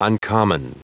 Prononciation du mot uncommon en anglais (fichier audio)
Prononciation du mot : uncommon